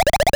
Speaking.wav